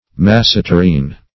masseterine - definition of masseterine - synonyms, pronunciation, spelling from Free Dictionary
\Mas"se*ter`ine\